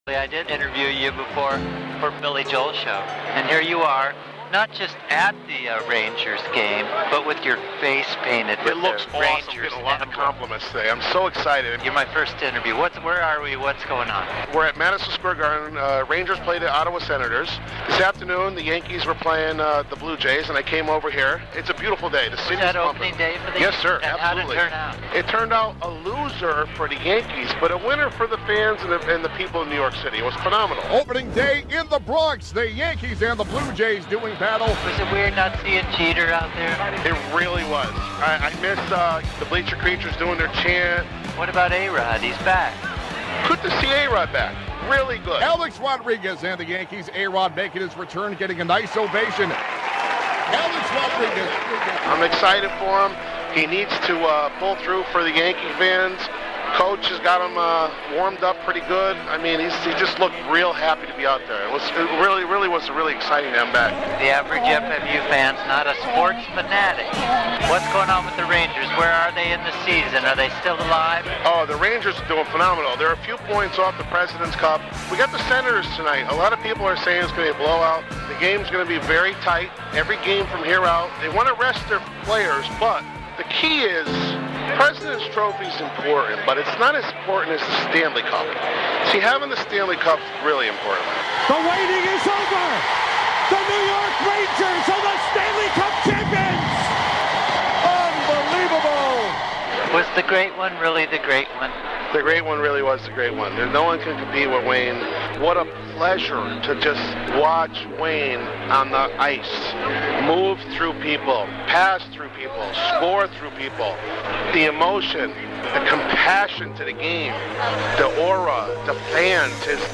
Street Interviews - Albino Mule - Almost Like Old Times from Apr 9, 2015
Set: Hanging Out In Front of Madison Square Garden
Set: Interview with a Cavs Fan